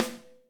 snare2.ogg